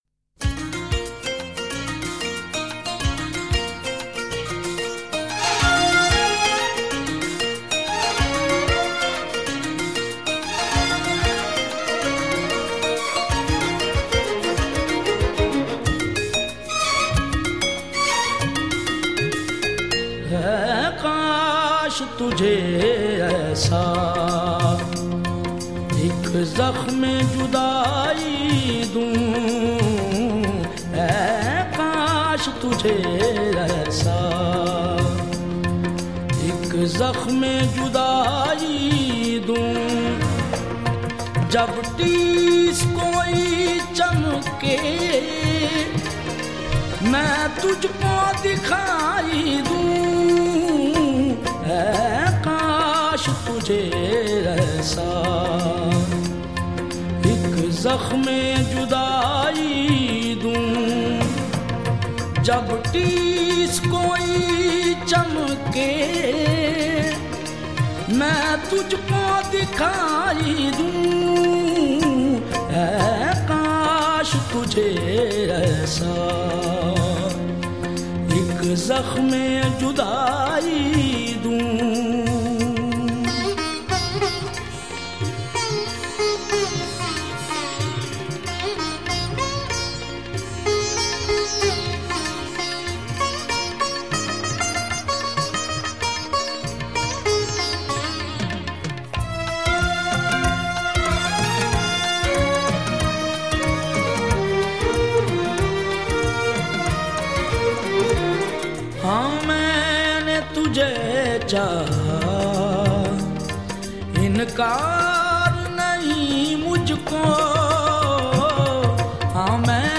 a traditional style of qawwali